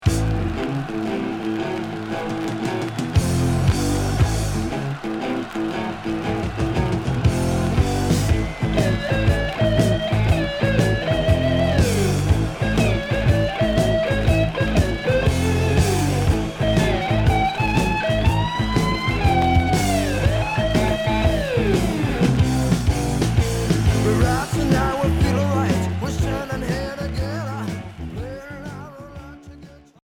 Heavy rock boogie Unique 45t retour à l'accueil